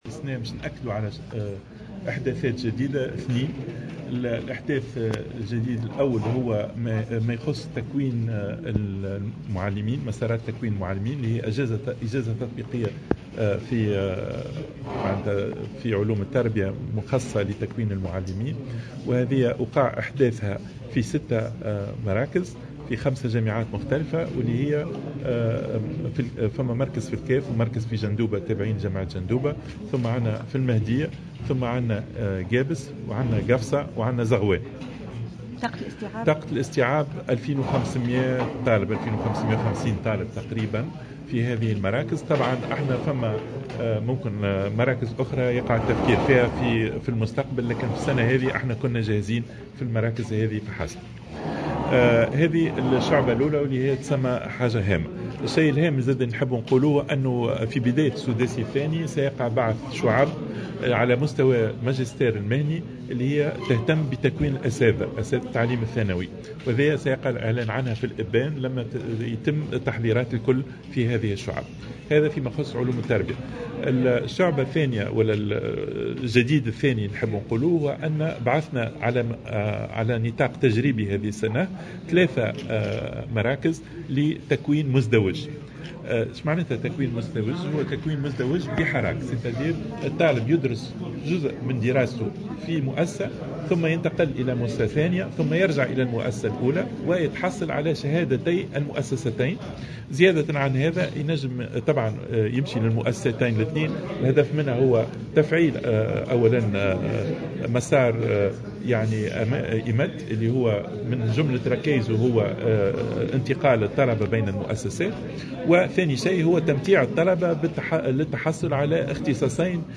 أعلن وزير التعليم العالي شهاب بودن في تصريح لمراسلة الجوهرة "اف ام" اليوم الثلاثاء عن بعث عدد هام من الشعب والإحداثات الجديدة هذه السنة .